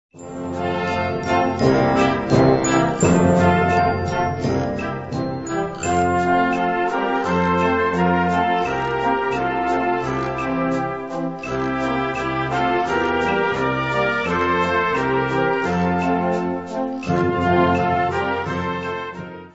Kategorie Blasorchester/HaFaBra
Unterkategorie Konzertmusik
Besetzung Flexi (variable Besetzung)
Besetzungsart/Infos 5part; Perc (Schlaginstrument)